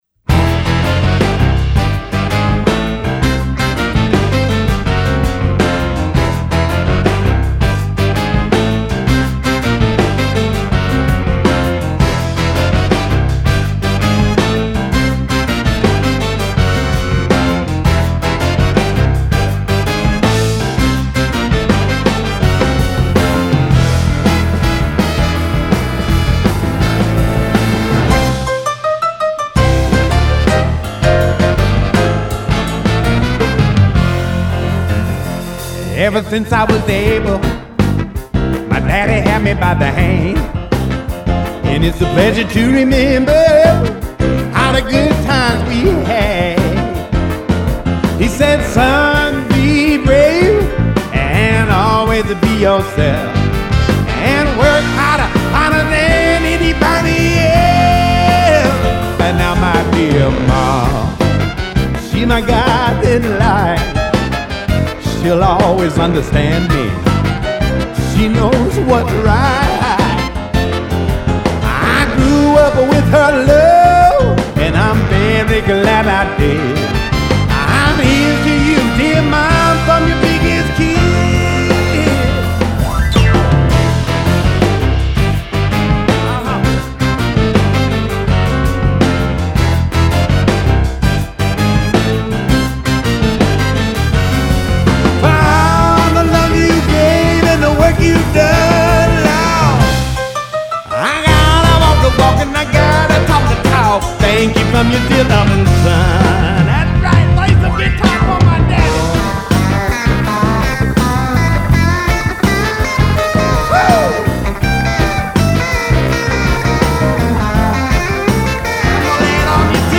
De fait cet album apparaît plus varié, plus doux.